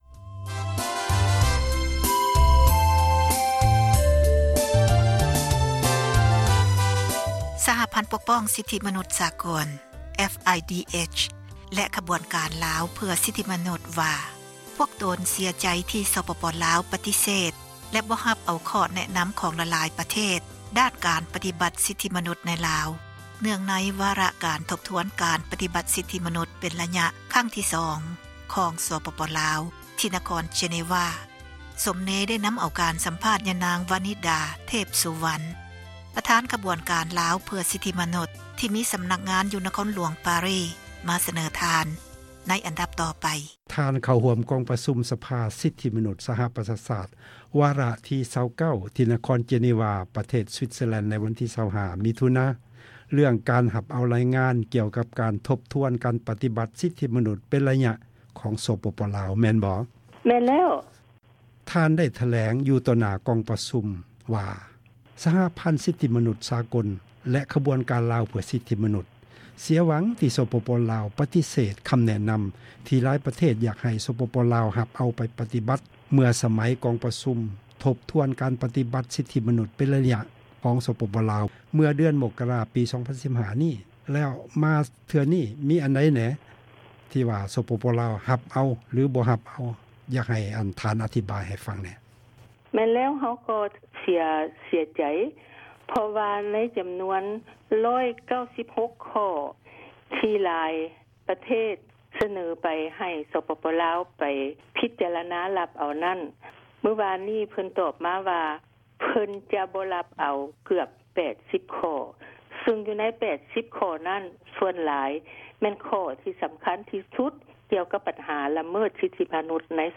ການ ສັມພາດ